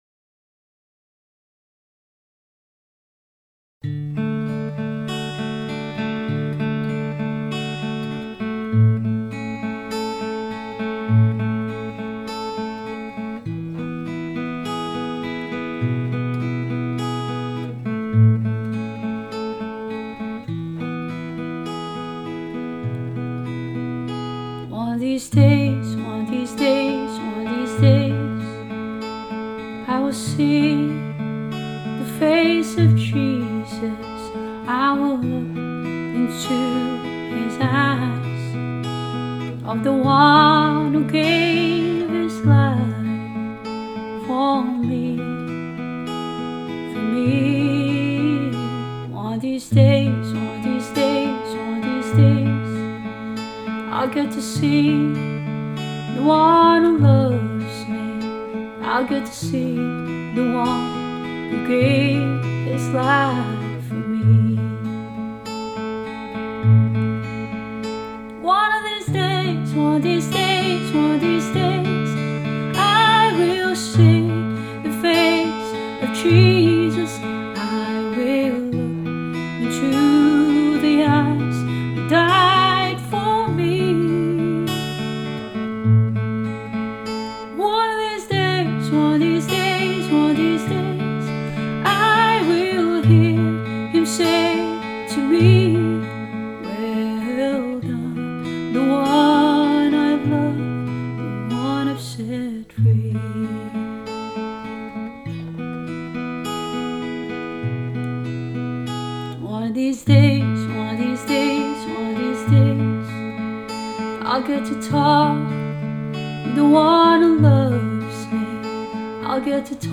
Guitar and voice